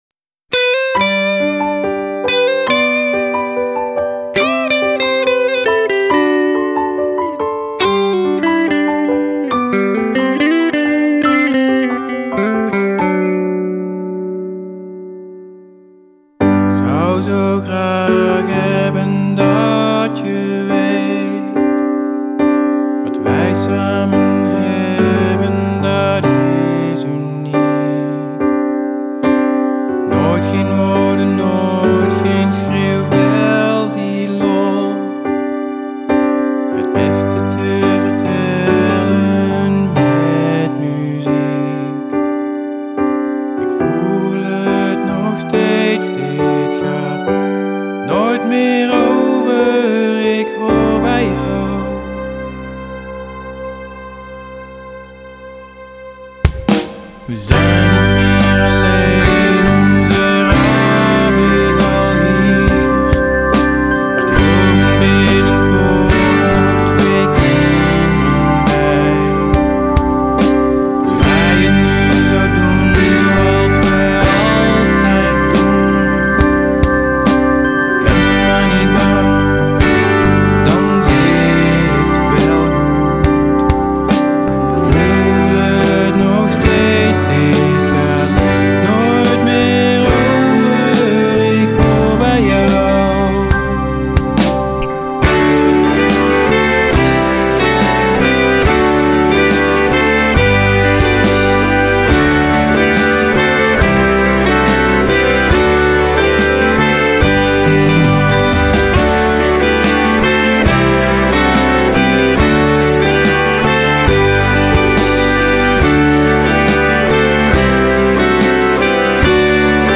Saxofoon